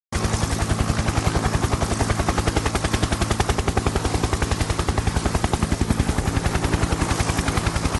Heroes at work: Army helicopter sound effects free download